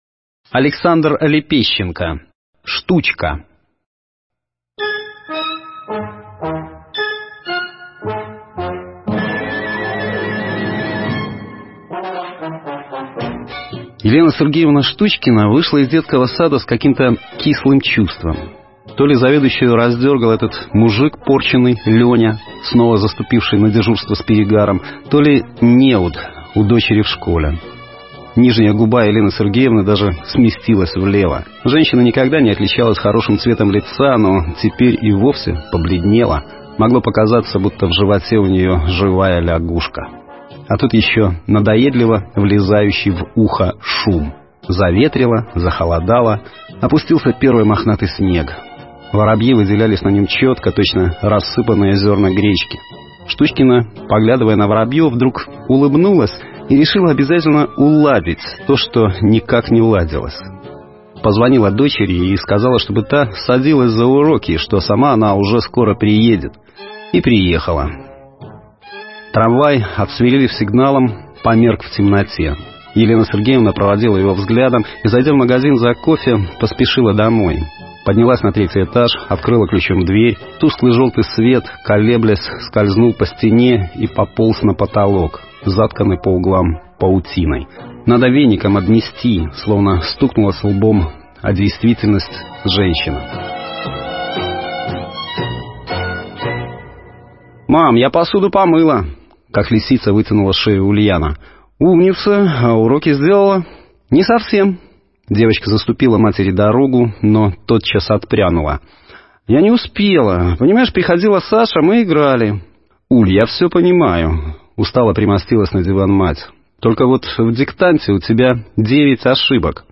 Аудиокниги
Аудио-Рассказы